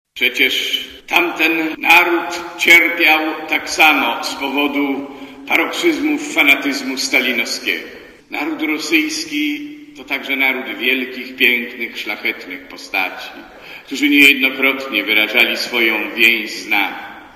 Mówi arcybiskup Józef Życiński